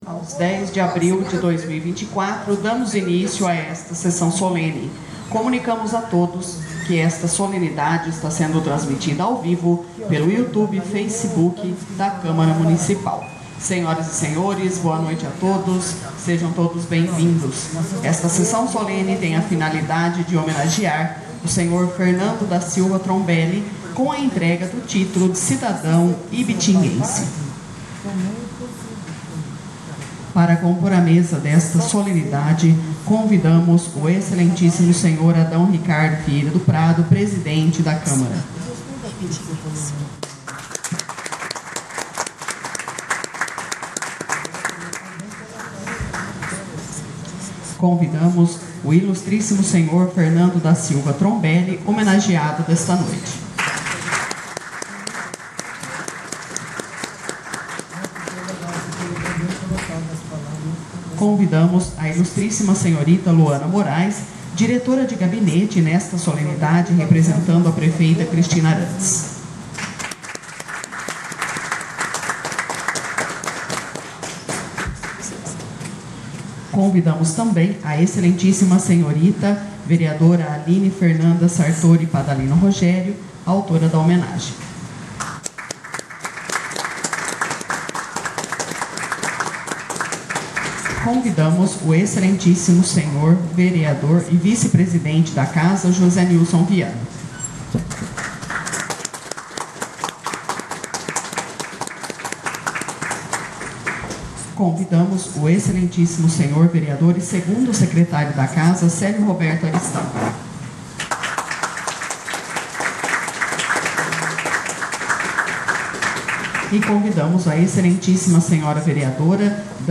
Sessões Solenes/Especiais